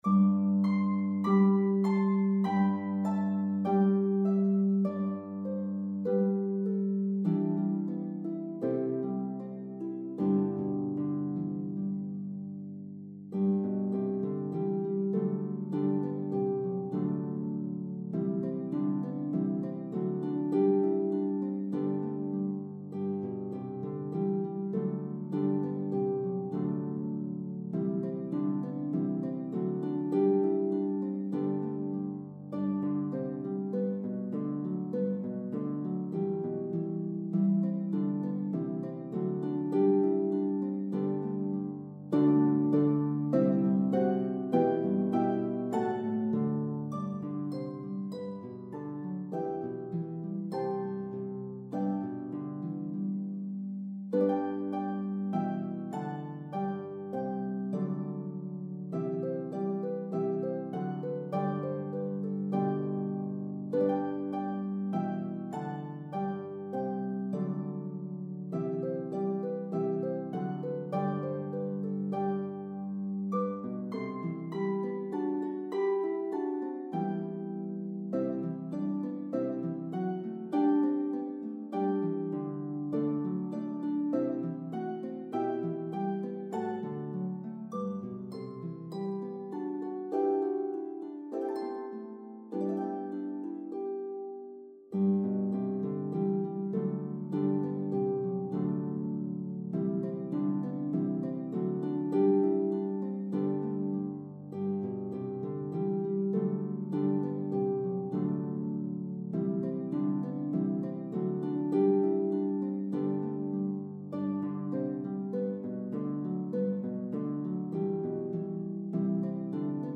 This arrangement has 5 verses in the key of G.
The melody is divided evenly between parts.